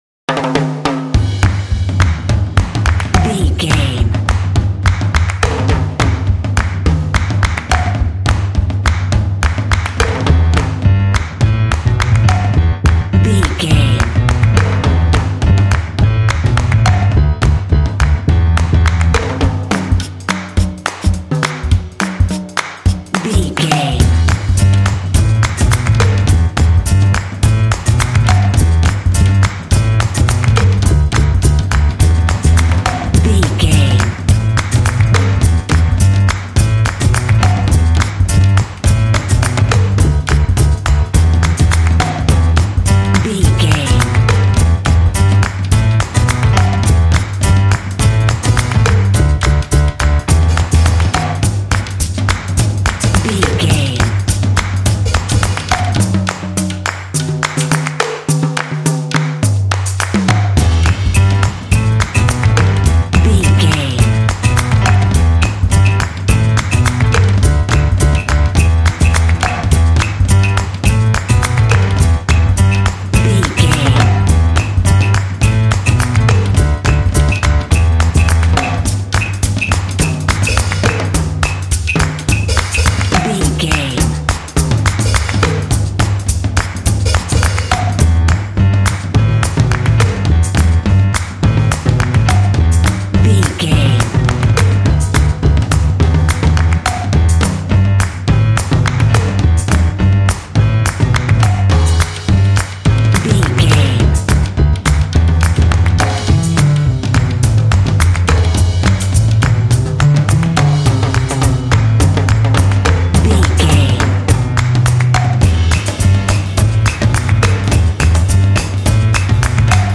Uplifting
Aeolian/Minor
driving
energetic
lively
cheerful/happy
drums
piano
percussive